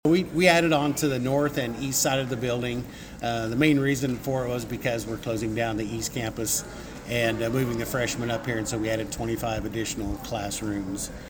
USD 383 board member Curt Herrman detailed the new additions at the open house.